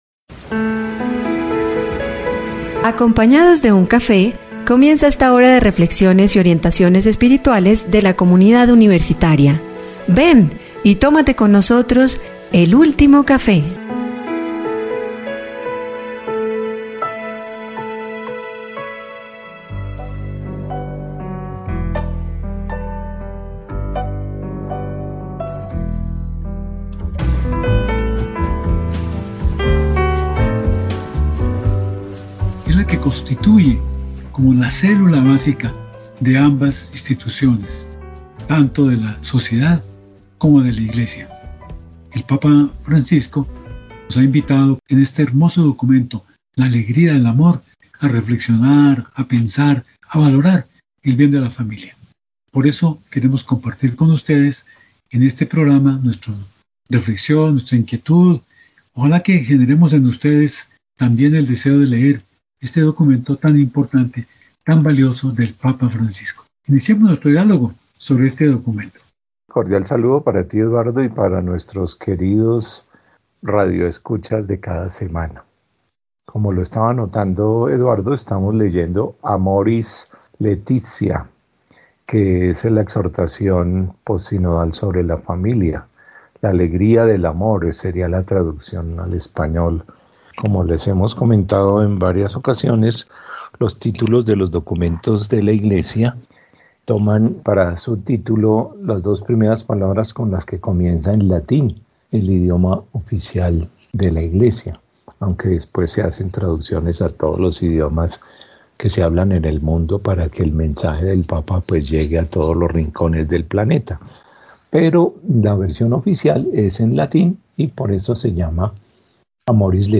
Conversan sobre este tema